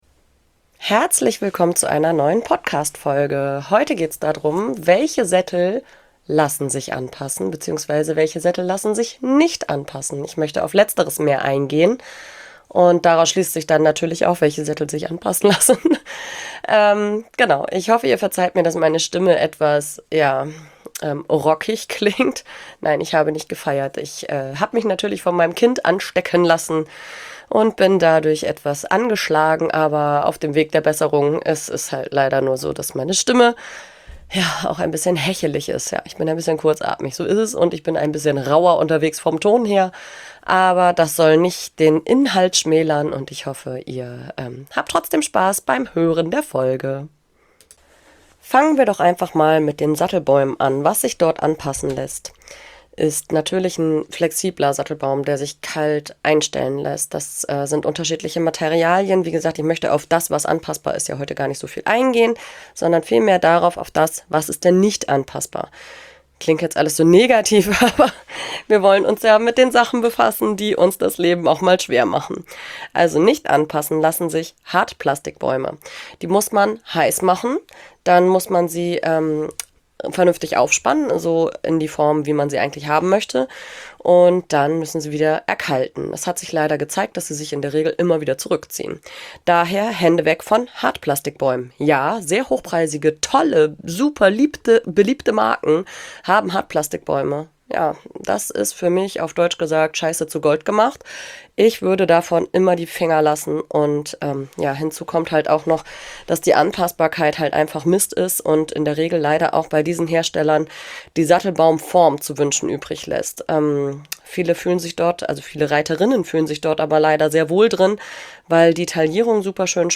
Hier war ein kleiner Tonfehler in der Folge, nun ist alles wieder schick hörbar! Heute erkläre ich euch, warum und wieso manche Sättel nicht anpassbar sind oder auch, wann ich sage: Sorry, ich würde ja gerne und technisch wäre es auch möglich, aber so hat es einfach leider keinen Sinn.